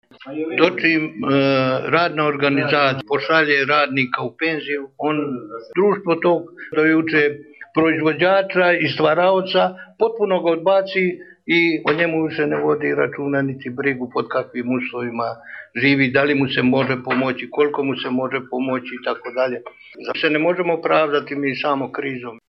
Anketa sa penzionerima u Budvi.